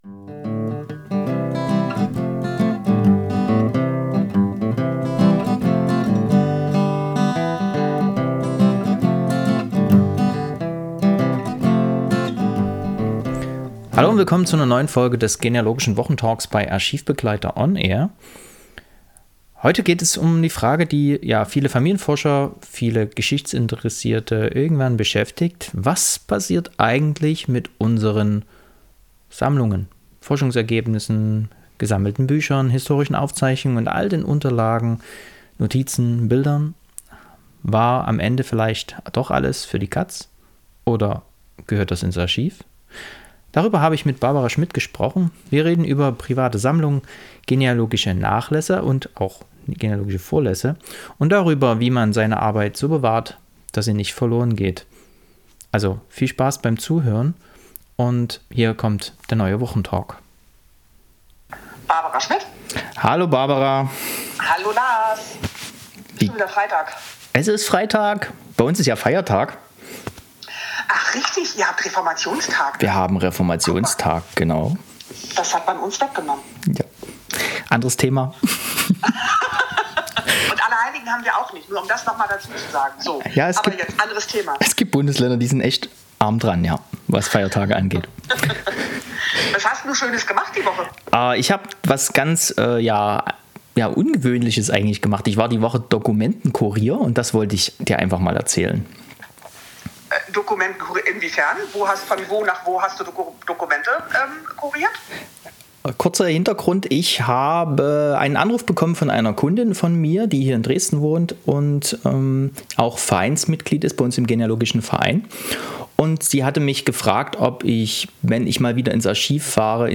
nachlassgespraech_final.mp3